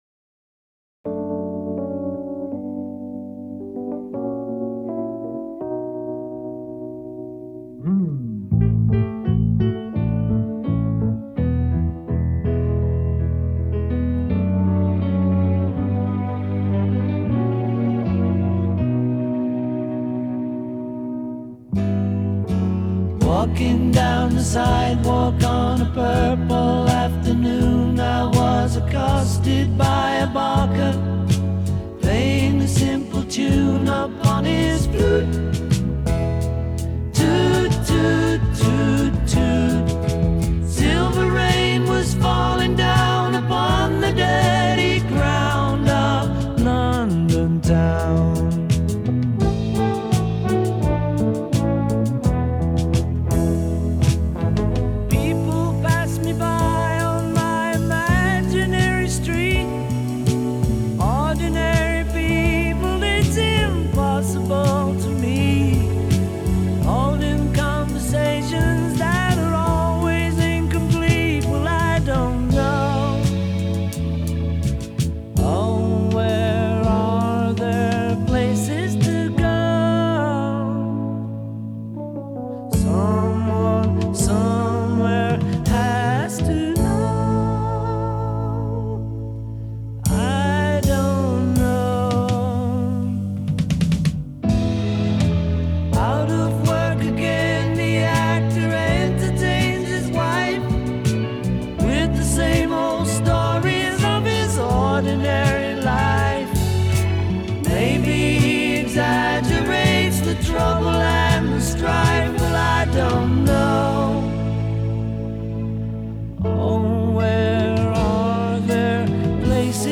Genre : Soul, Folk